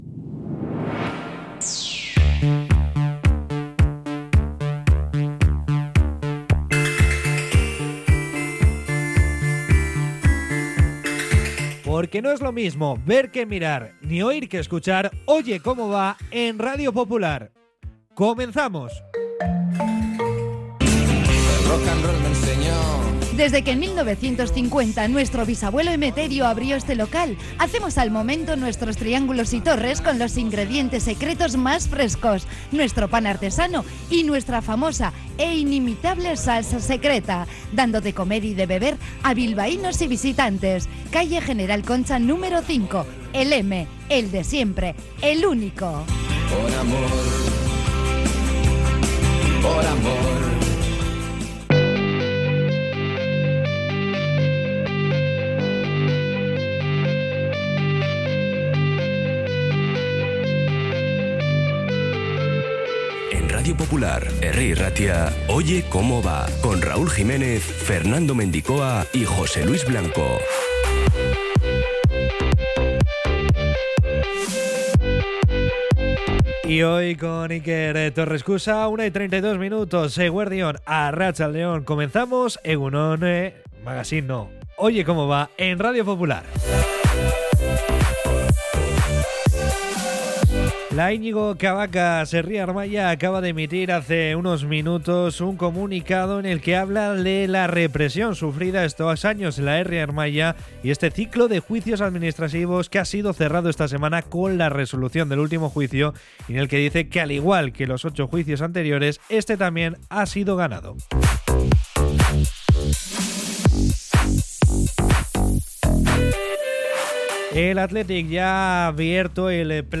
De 13:30 a 14:00 resumen informativo de la jornada con entrevistas y las voces de los protagonistas.